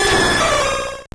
Cri de Ho-Oh dans Pokémon Or et Argent.